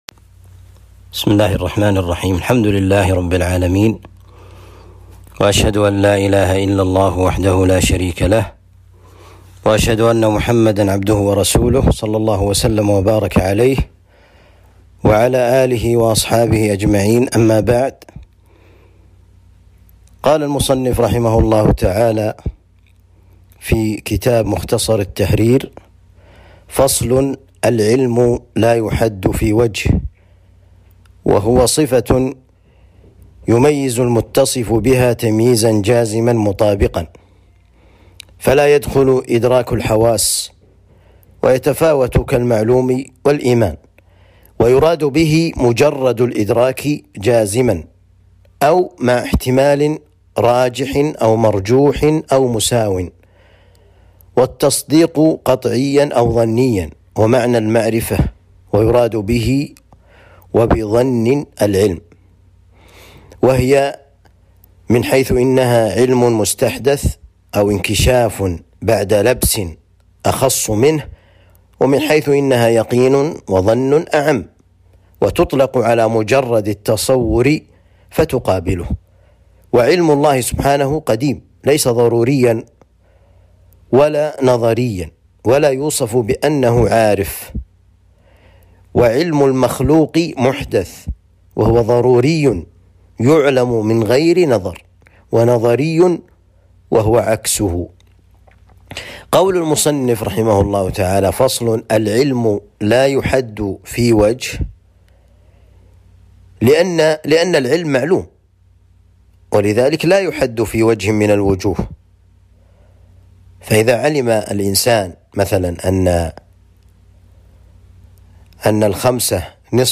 التعليق على كتاب مختصر التحرير في أصول الفقه الدروس التعليق على كتاب مختصر التحرير في أصول الفقه المقطع 5.